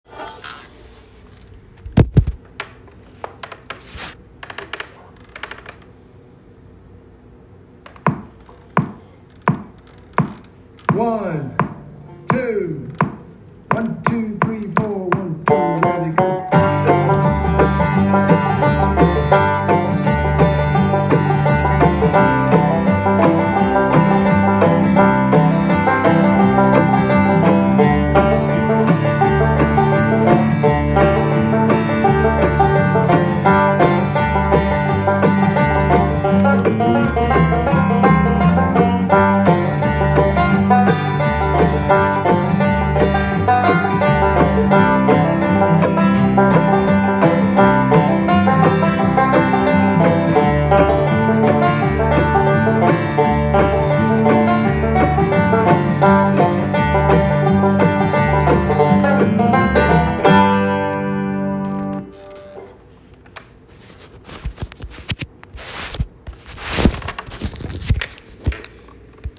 I have attached a recording of me playing these rolls at moderate speed.
They are kind of syncopated, have some swing and are a little bluesy.